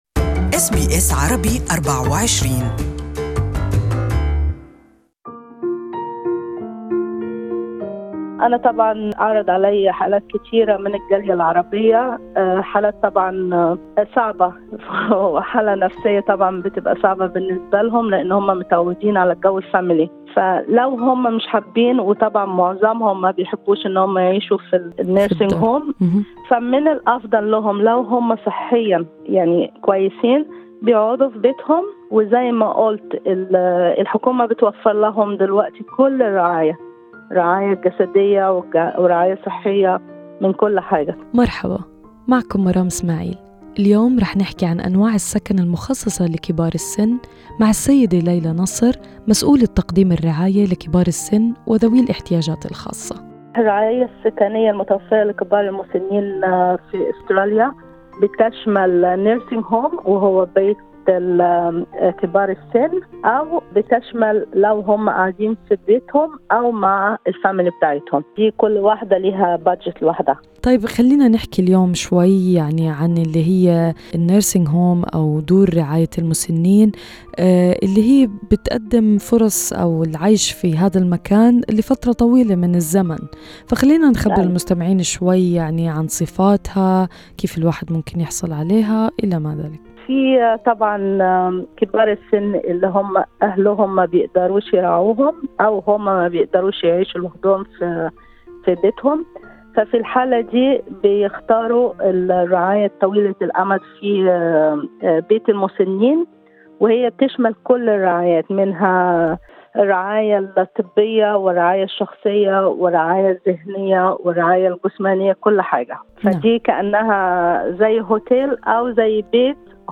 وستستمعون في كل حلقة الى بعض من قصص كبار السن وتجاربهم مع الخدمات التي يستفيدون منها في استراليا لتحسن من نوعية الحياة التي يعيشونها بالإضافة الى بعض من النصائح المقدمة من مسؤولي تقديم الرعاية للمسنين.